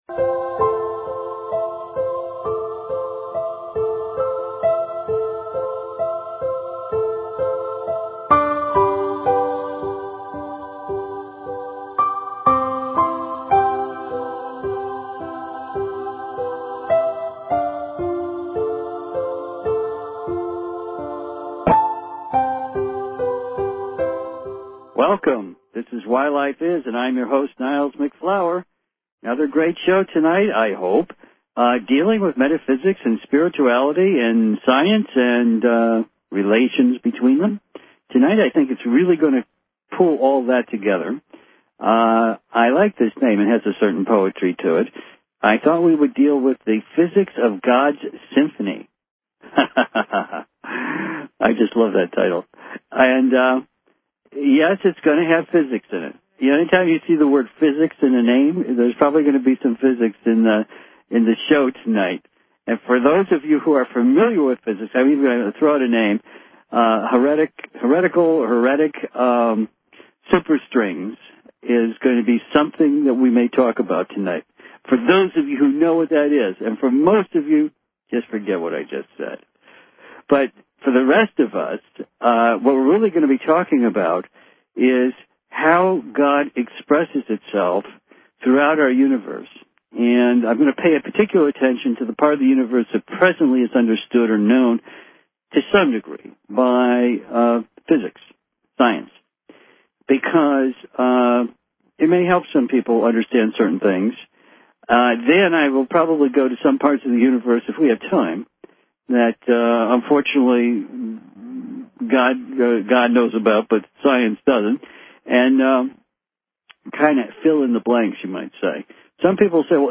Talk Show Episode, Audio Podcast, Why_Life_Is and Courtesy of BBS Radio on , show guests , about , categorized as